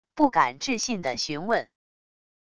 不敢置信地询问wav音频